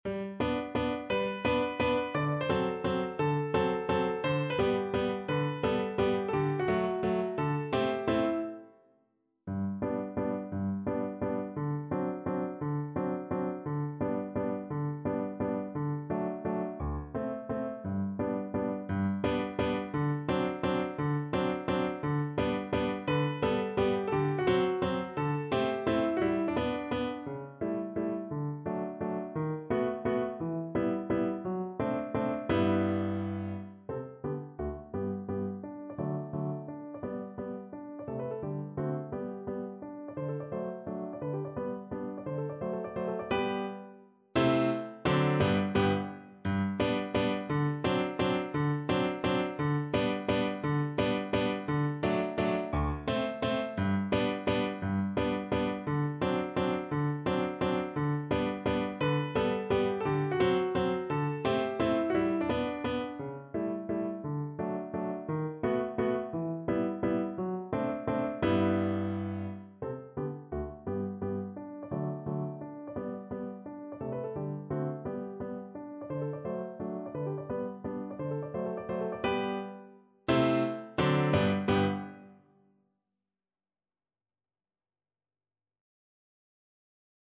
Verdi: La donna è mobile (na wiolonczelę i fortepian)
Symulacja akompaniamentu